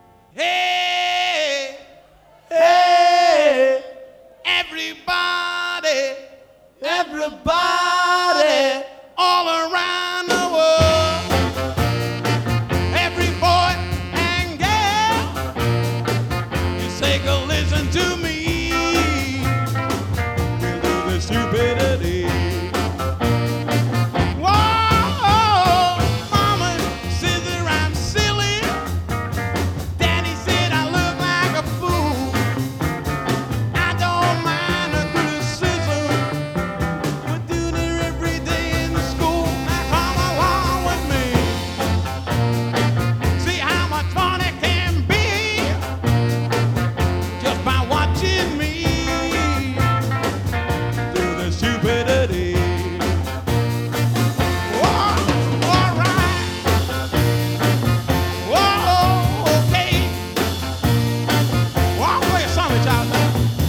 A cover